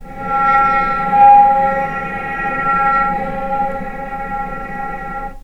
healing-soundscapes/Sound Banks/HSS_OP_Pack/Strings/cello/sul-ponticello/vc_sp-C#4-pp.AIF at b3491bb4d8ce6d21e289ff40adc3c6f654cc89a0
vc_sp-C#4-pp.AIF